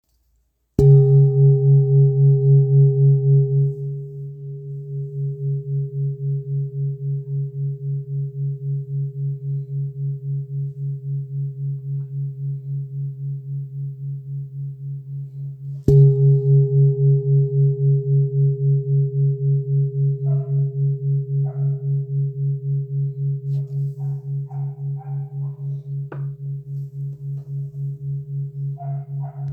Kopre Singing Bowl, Buddhist Hand Beaten, Antique Finishing